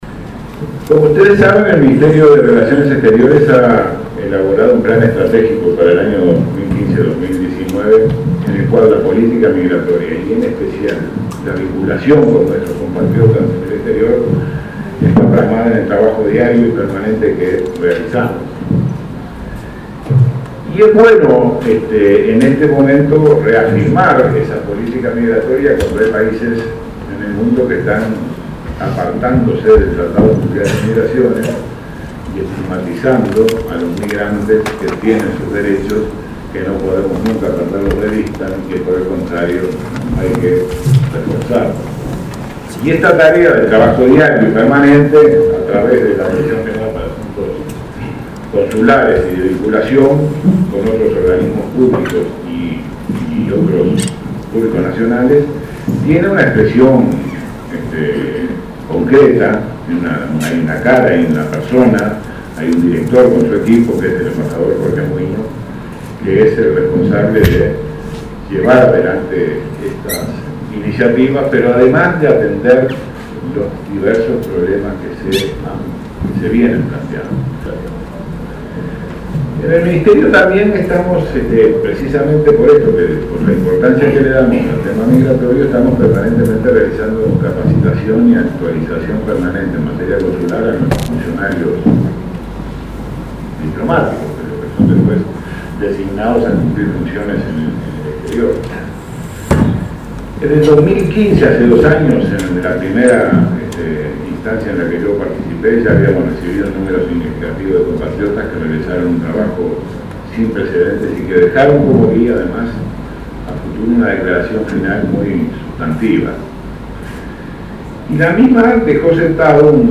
El canciller Nin Novoa abrió un encuentro mundial de Consejos Consultivos y el de las asociaciones de uruguayos residentes en el exterior. En ese marco, reafirmó la política migratoria del país, destacó que en este período se reformó el área de atención al usuario dependiente de Asuntos Consulares y se triplicó dicha atención.